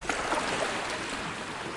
sea-wave-34088.mp3